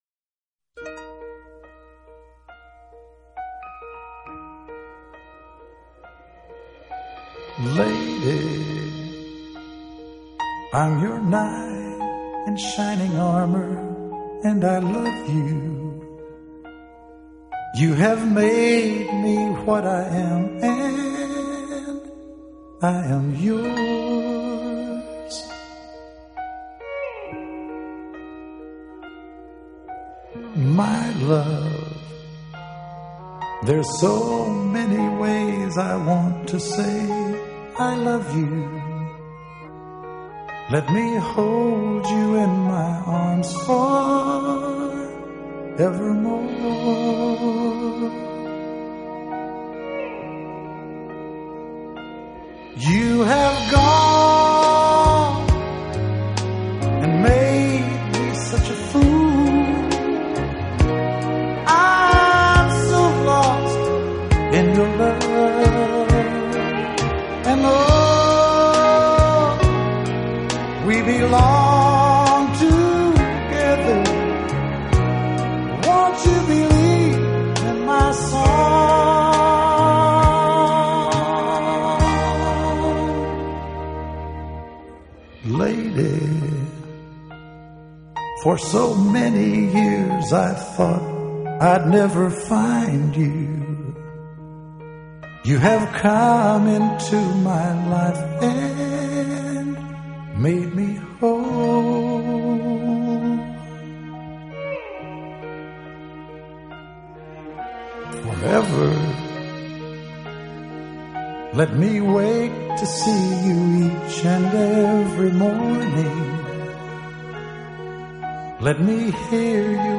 Genre: Country / Romantic
称乡村音乐教父的就是他了, 原始质朴的乡村风格，柔美易感的抒情曲式，
温暖厚实的诚挚嗓音及亲和优雅的迷人风范。